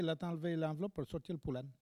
Langue Maraîchin
locutions vernaculaires
Catégorie Locution